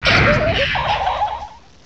sovereignx/sound/direct_sound_samples/cries/scovillain.aif at master
downsample cries